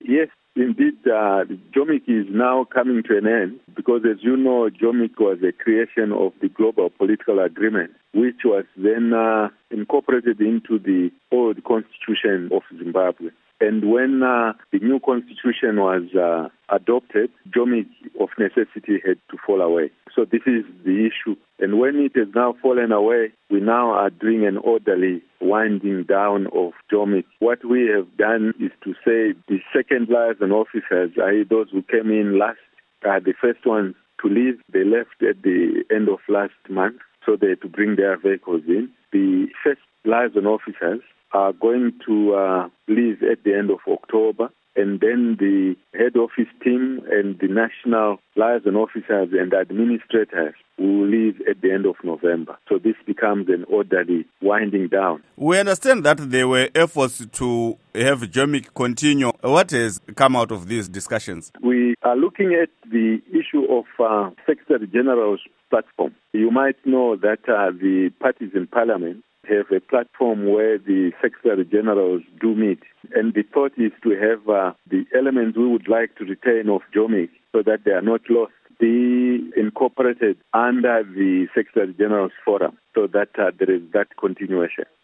Interview With Elton Mangoma